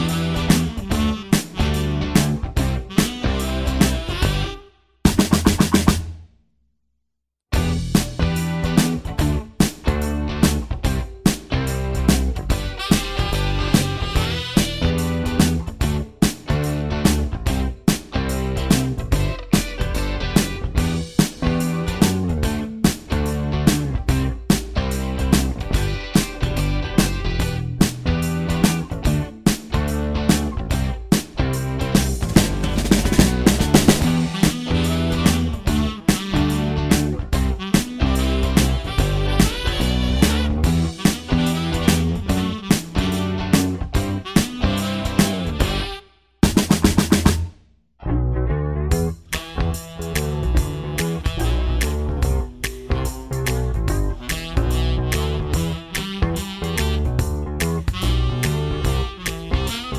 no Backing Vocals Irish 3:30 Buy £1.50